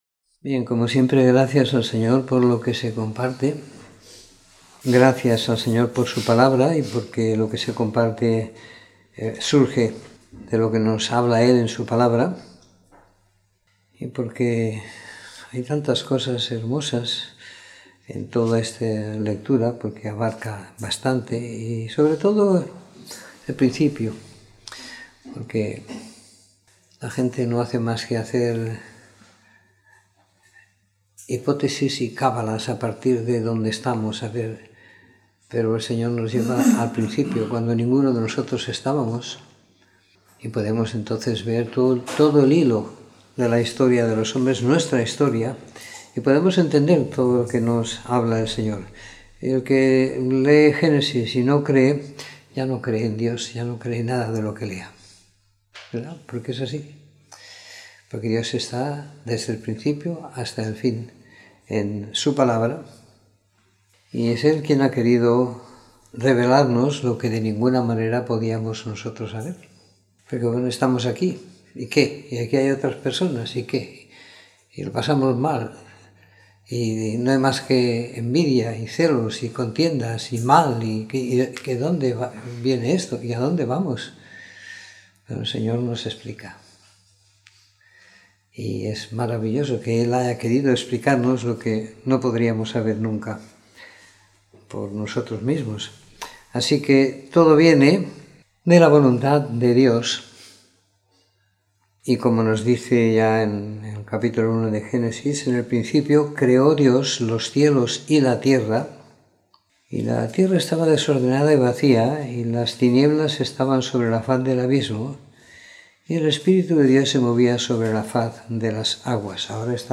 Comentario en Génesis 1-3, 6 - 04 de Enero de 2019